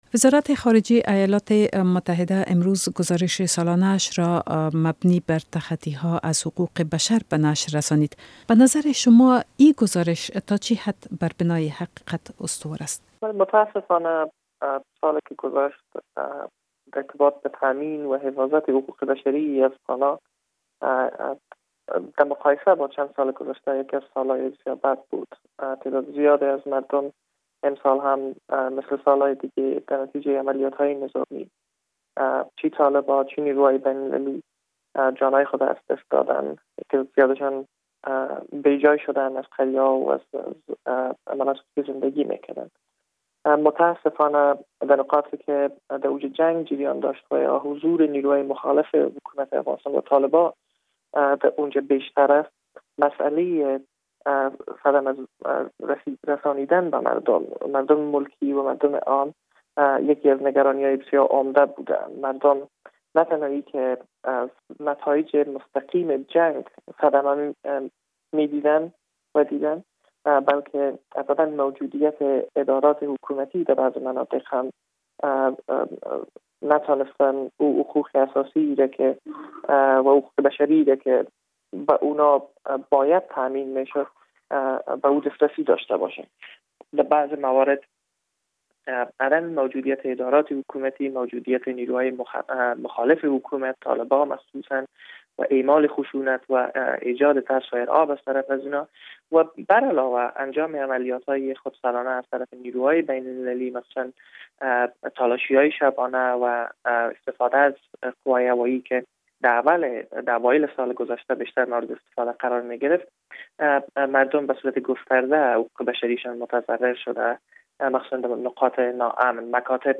مصاحبه با محمد نادر نادری در مورد گزارش سالانهء وزارت خارجهء امریکا